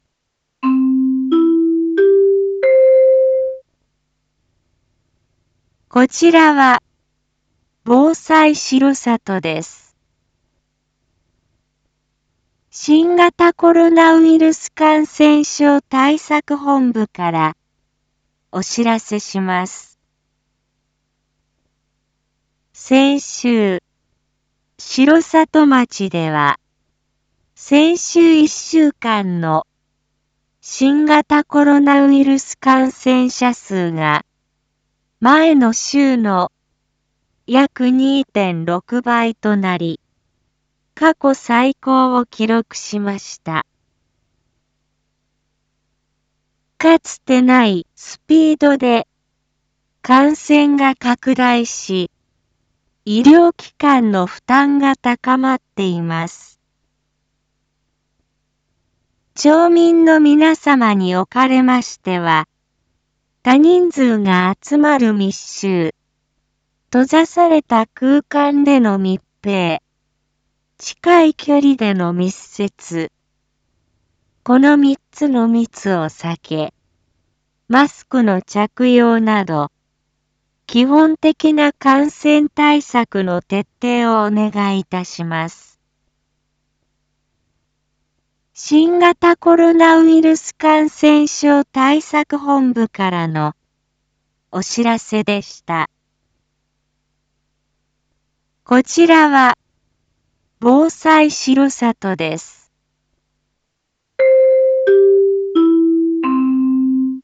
一般放送情報
Back Home 一般放送情報 音声放送 再生 一般放送情報 登録日時：2022-08-02 07:01:48 タイトル：新型コロナウイルス感染症防止 インフォメーション：こちらは防災しろさとです。